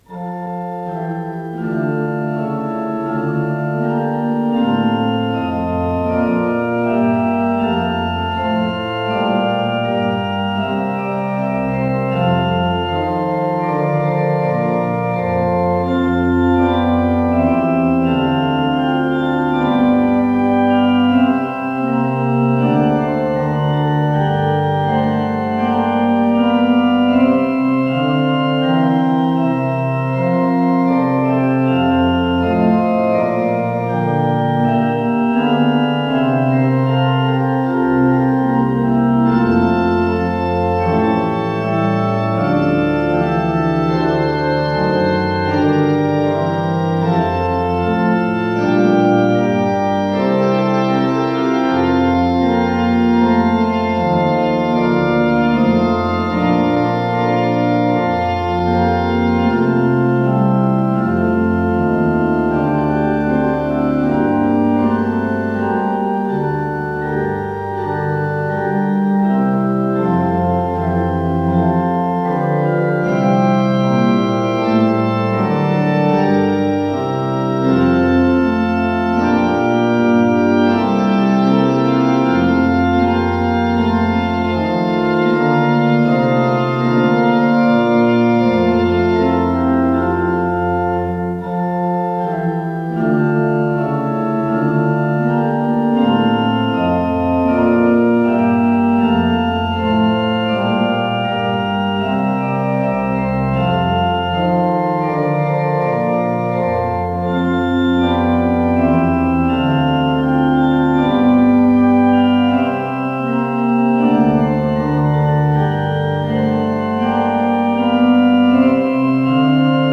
с профессиональной магнитной ленты
ПодзаголовокФа мажор
ИсполнителиГарри Гродберг - орган
ВариантДубль моно